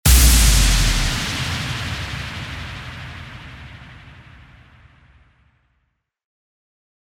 FX-840-IMPACT
FX-840-IMPACT.mp3